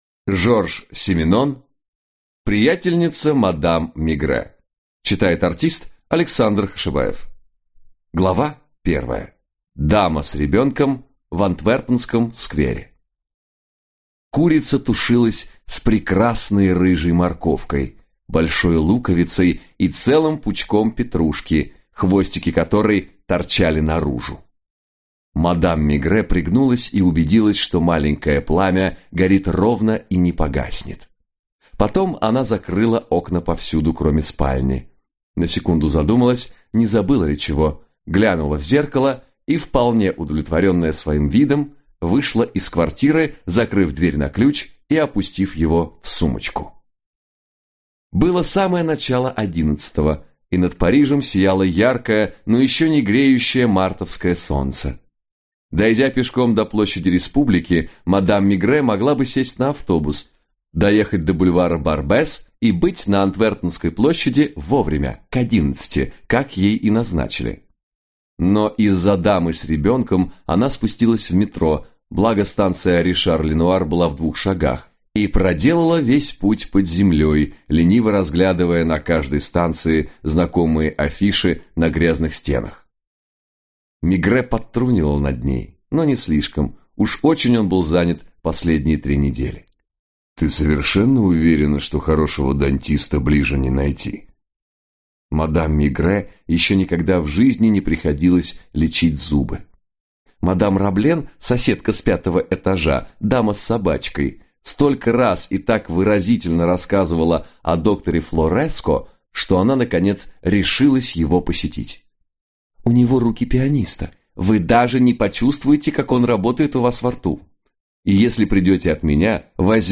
Аудиокнига Приятельница мадам Мегрэ | Библиотека аудиокниг